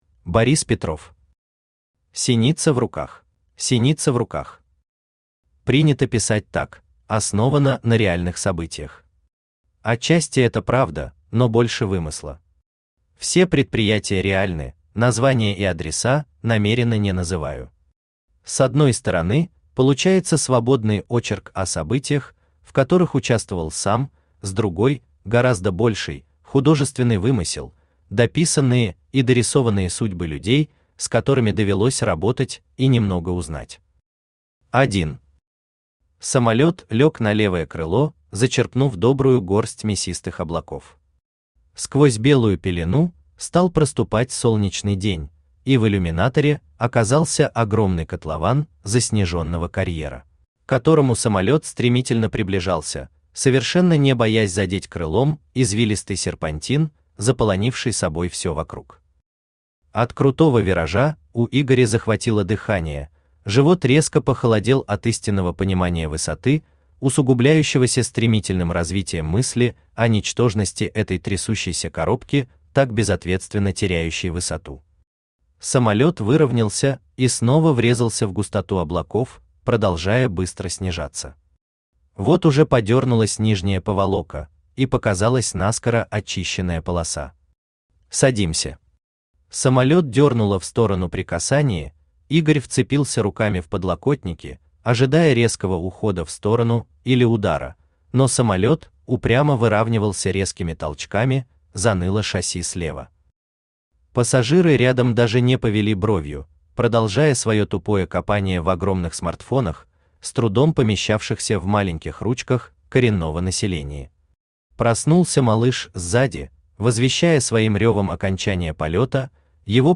Аудиокнига Синица в руках | Библиотека аудиокниг
Aудиокнига Синица в руках Автор Борис Петров Читает аудиокнигу Авточтец ЛитРес.